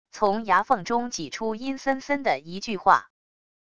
从牙缝中挤出阴森森的一句话wav音频